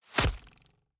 Bow Impact Hit 3.ogg